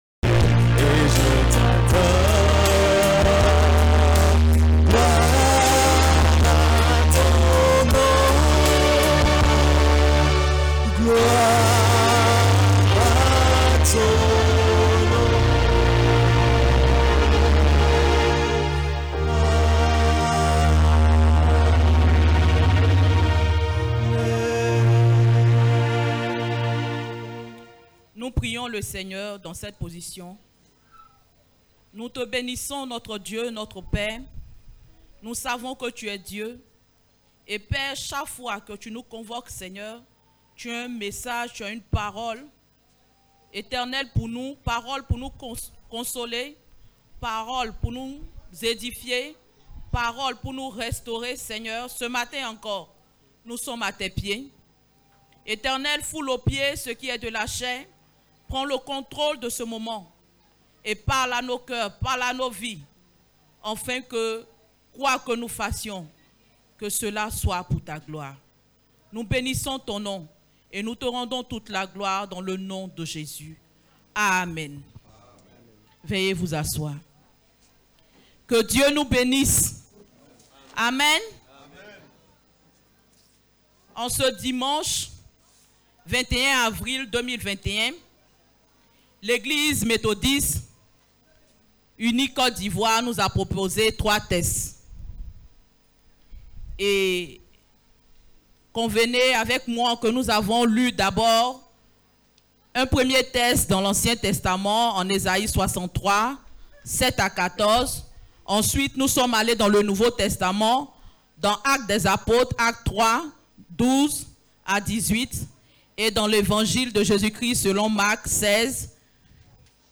Cet enregistrement est le sermon